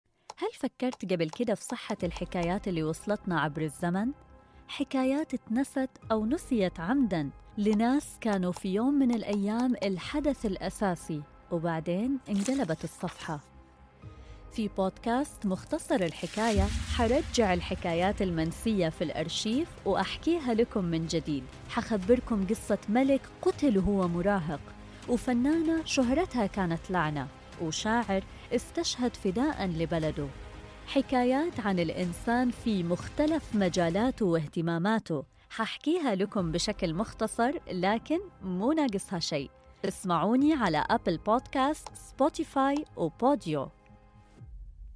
هنا، لا نغوص في التفاصيل من أجل الإطالة… بل نبحث عن جوهر الحكاية، ونقدّمه لك بصوت واحد، كل أسبوعين. في البرومو، تتعرّف على هوية البرنامج، أسلوب السرد، ولماذا قررنا أن نروي القصص التي لم تأخذ حقها… القصص التي تستحق أن تُروى من جديد، ولو باختصار.